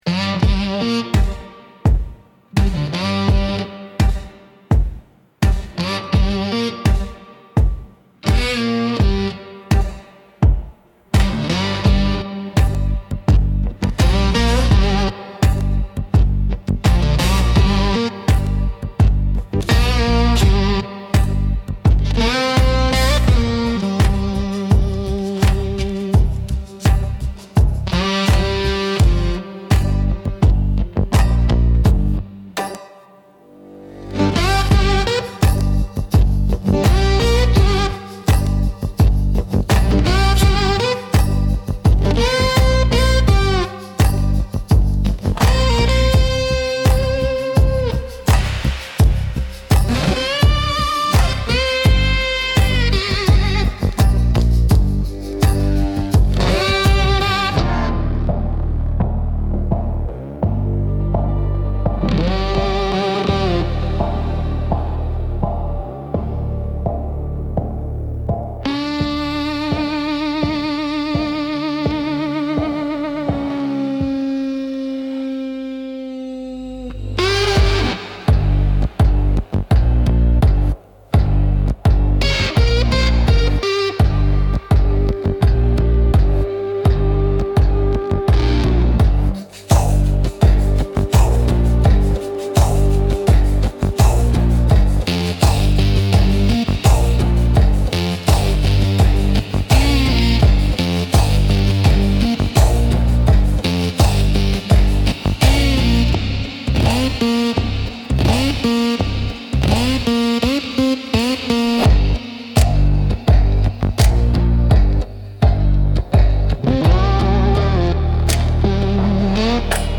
Instrumental - Midnight Cottonmouth